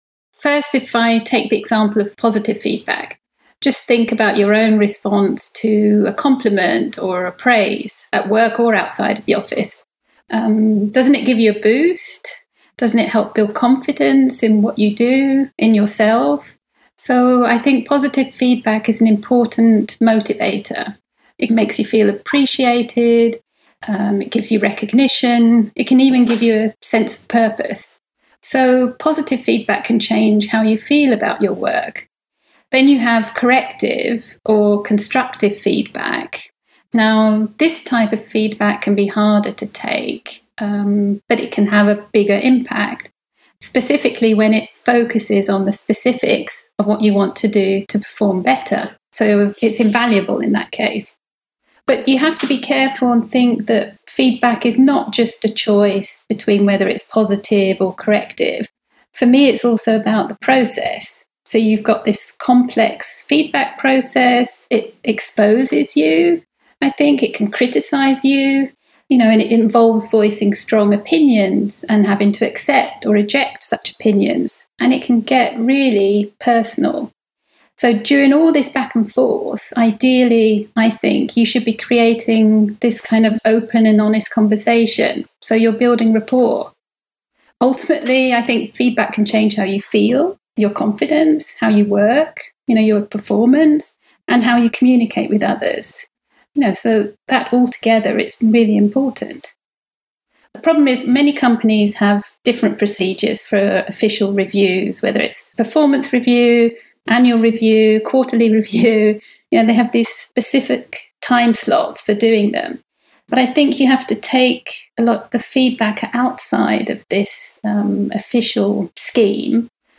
Business Skills - Interview